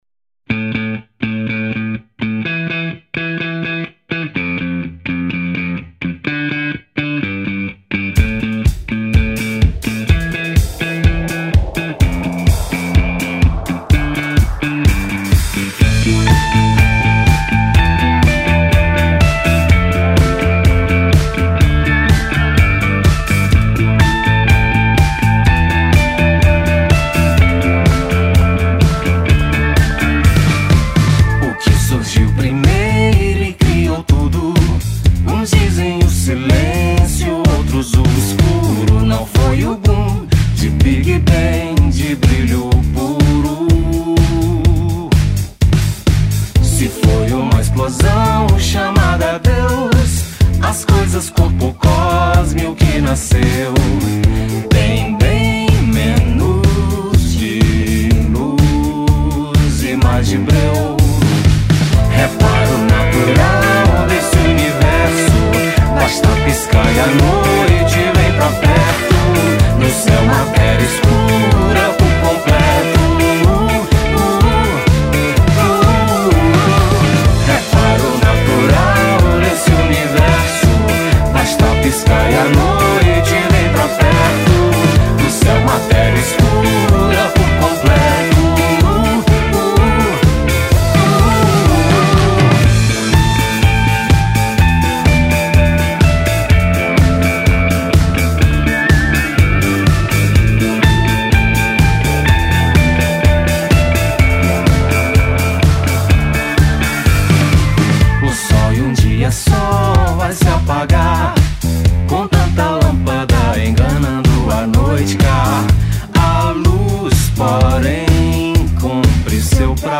1273   03:45:00   Faixa:     Rock Nacional